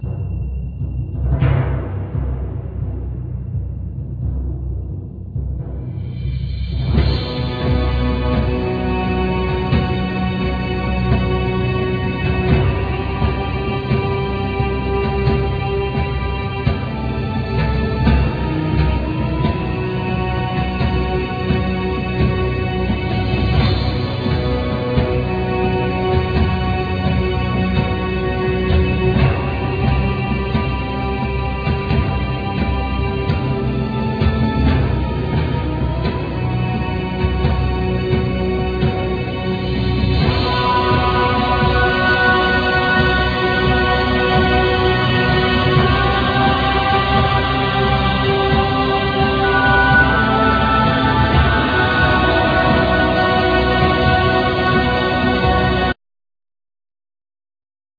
Backing vocals,Dulcimer,Drums,Programming